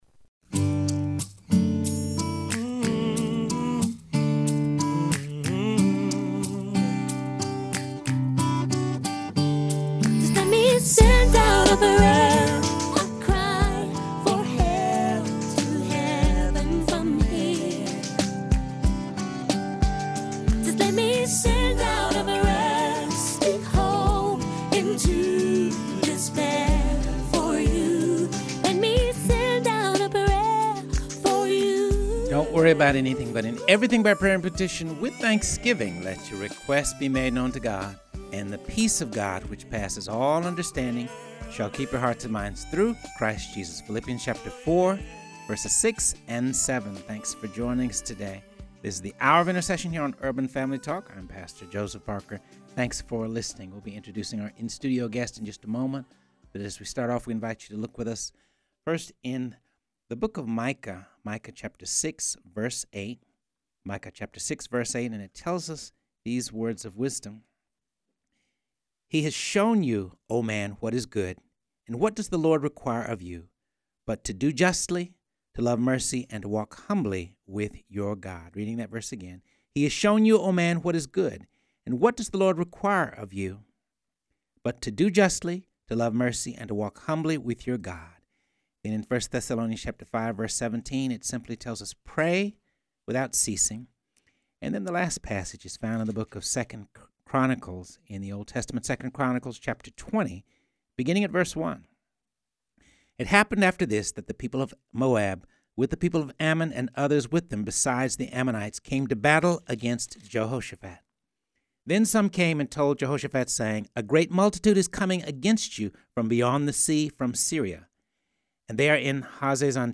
talks with in-studio guest